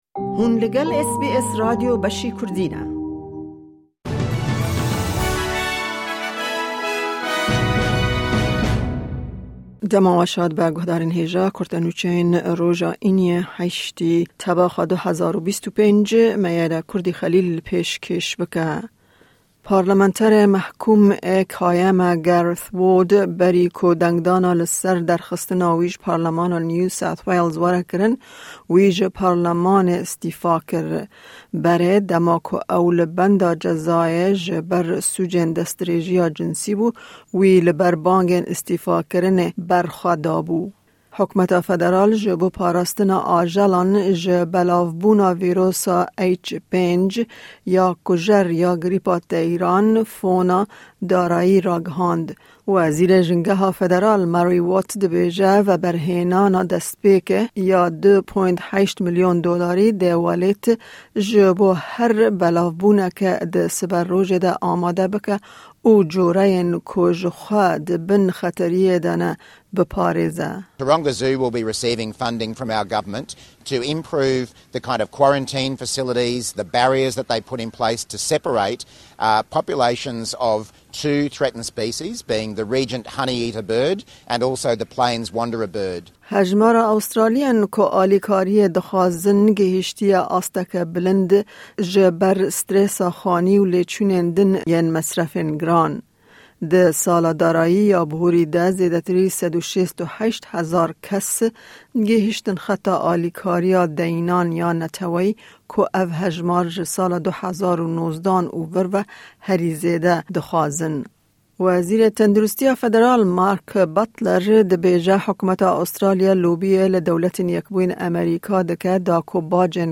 Kurte Nûçeyên roja Înî 8î Tebaxa 2025
Hikûmeta Federal bi mîlyonan dolar ji bo parastina li dijî cureyeke kujer ya grîpa teyrann terxan dike. Ew nûçeyana û nûçeyên din di bûletenê de hene.